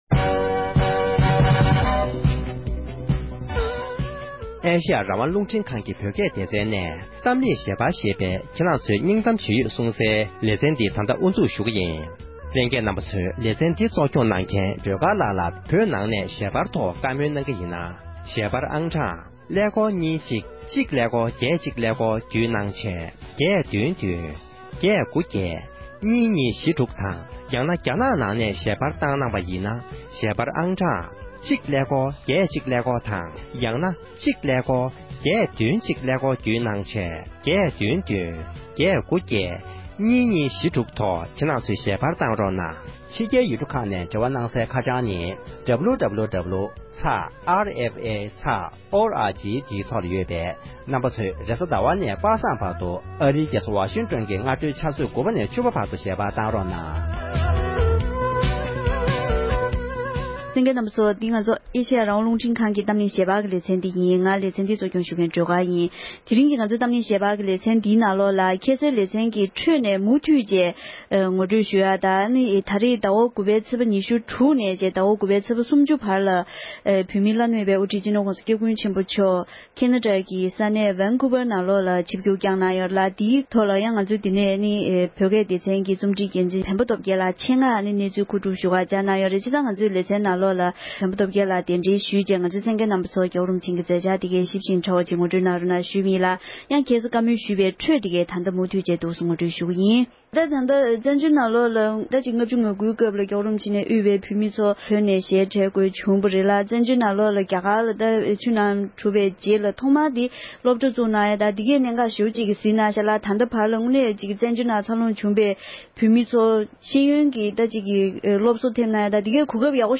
འབྲེལ་ཡོད་མི་སྣའི་ལྷན་གླེང་མོལ་གནང་བའི་ལེ་ཚན་གཉིས་པར་གསན་རོགས༎